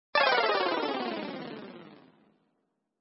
主角没了最后生命音效.mp3